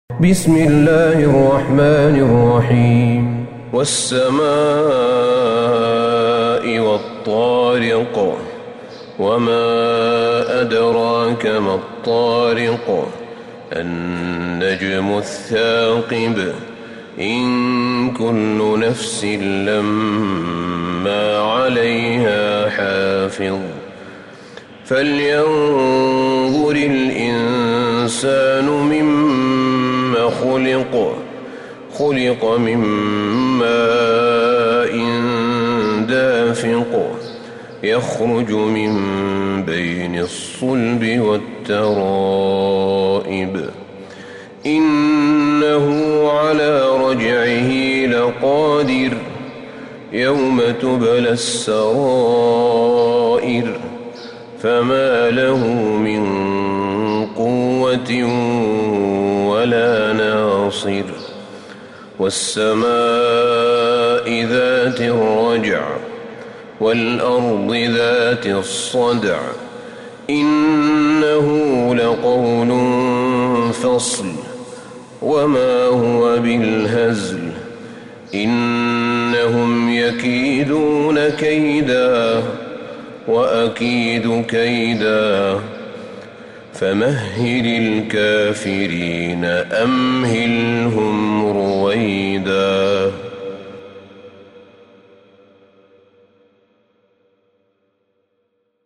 سورة الطارق Surat At-Tariq > مصحف الشيخ أحمد بن طالب بن حميد من الحرم النبوي > المصحف - تلاوات الحرمين